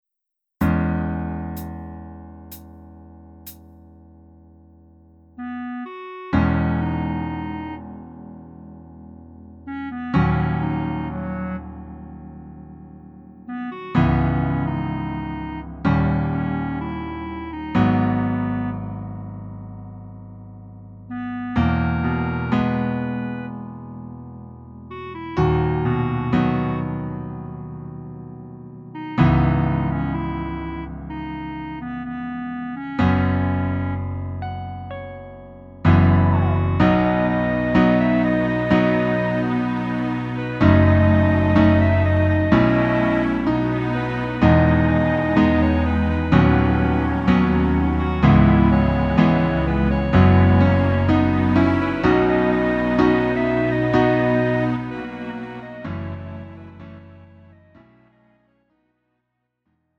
음정 -1키 3:32
장르 가요 구분 Lite MR